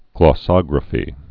(glô-sŏgrə-fē, glŏ-)